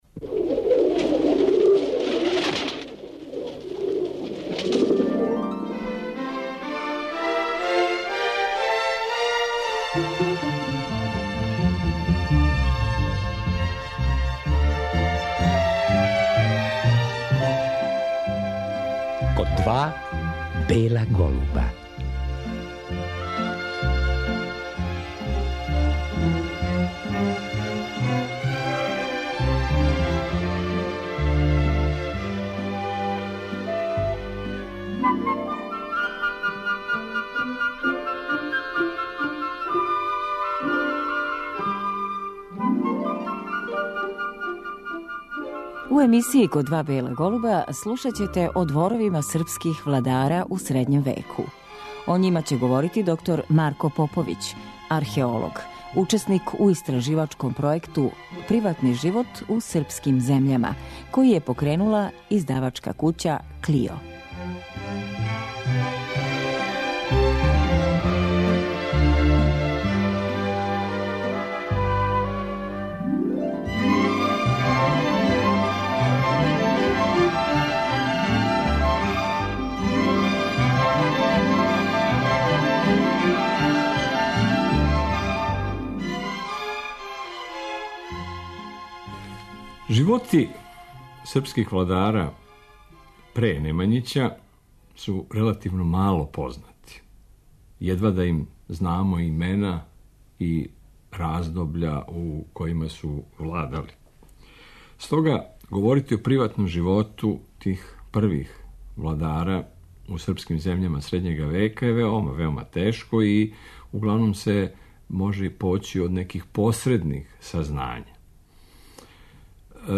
археолог.